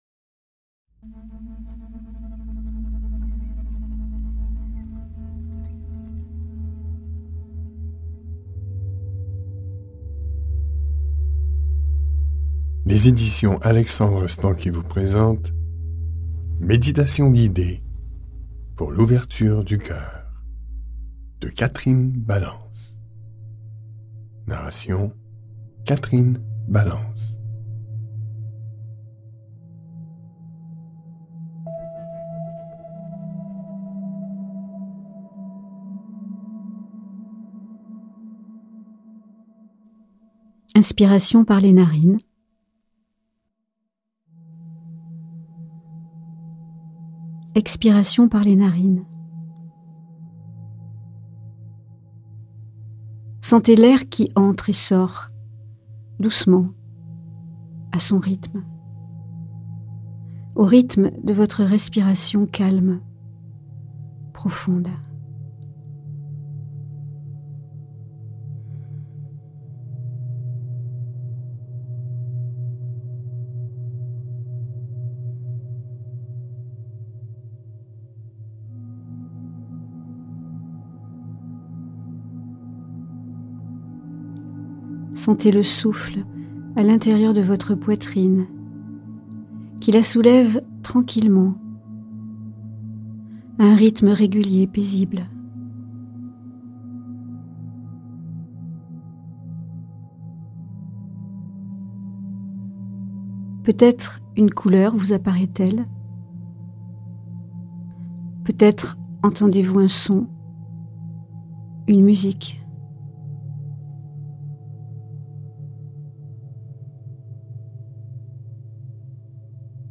Méditations guidées pour l'ouverture du cœur mp3
Diffusion distribution ebook et livre audio - Catalogue livres numériques
Les méditations proposées dans ce livre audio vous invitent à porter plus d’attention à votre cœur, à le soutenir, et favoriseront votre bien-être, en entretenant un sentiment de paix intérieure.